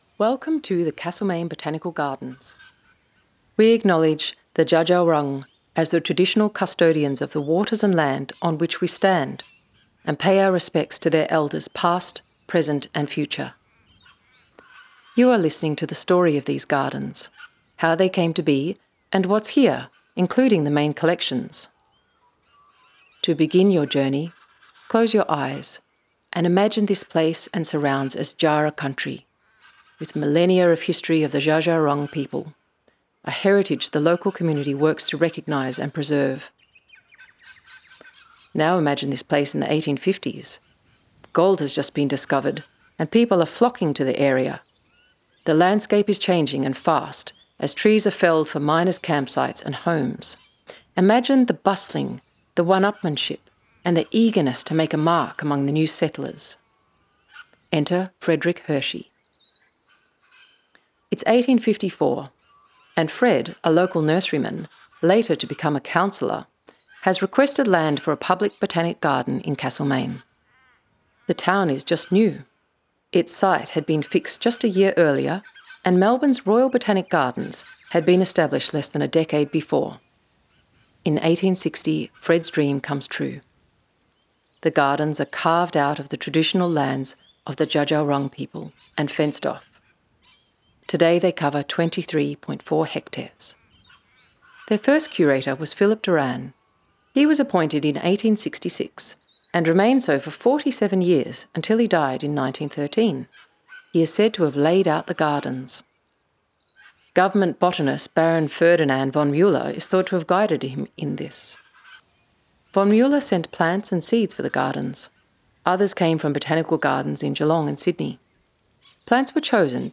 Audio tour
cbg-audio-guide-welcome.wav